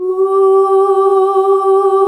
UUUUH   G.wav